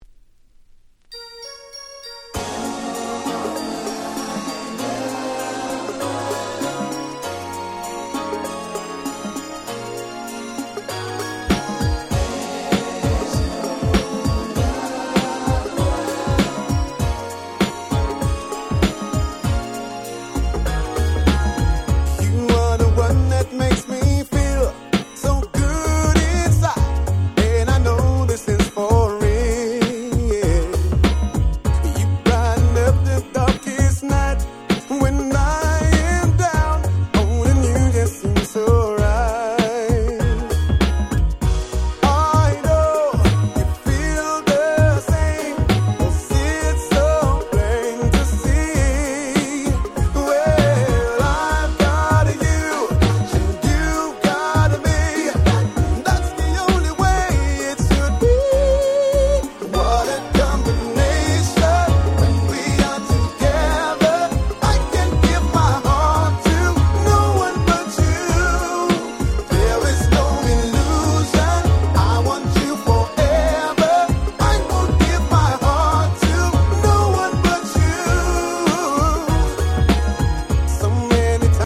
Super Nice UK R&B !!